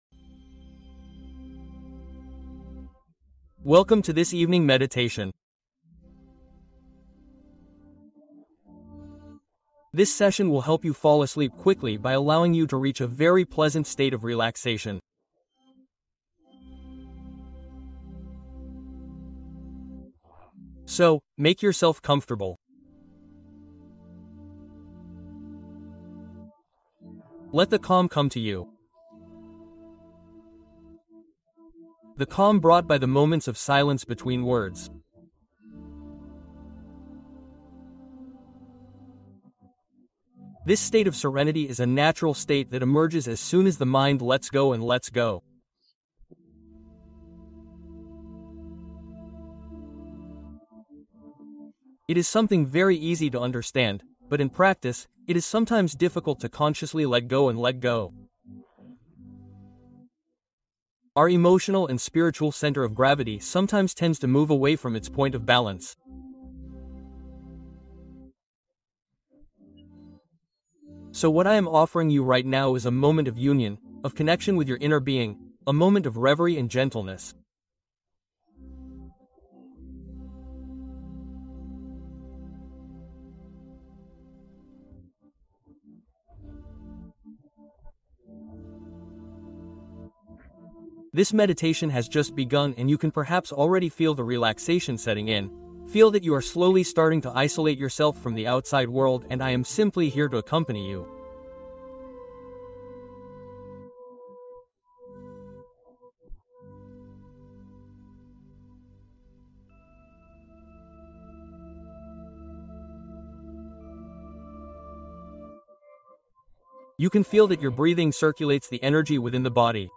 Sommeil Profond Et Réparateur Assuré Ce Soir | La Méditation Qui Répare Votre Nuit